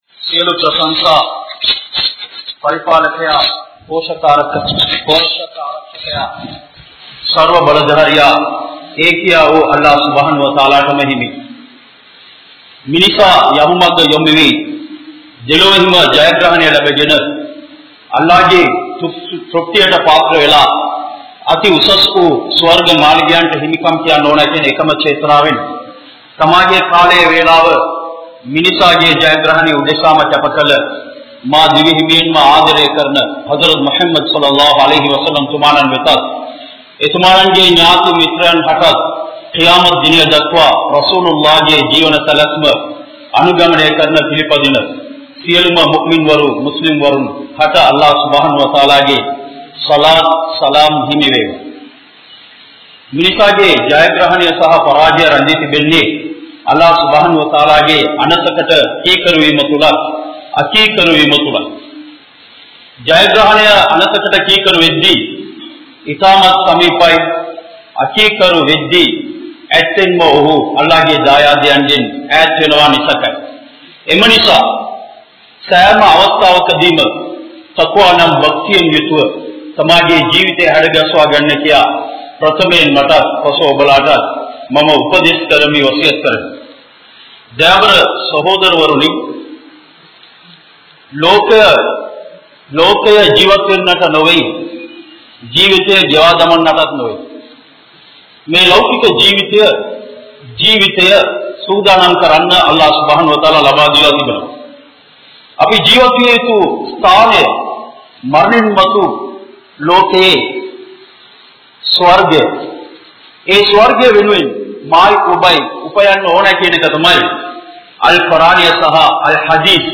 Allahvin Arutkodai (අල්ලාහ්ගේ වරප්රසාදයන්) | Audio Bayans | All Ceylon Muslim Youth Community | Addalaichenai
Kandy, Kattukela Jumua Masjith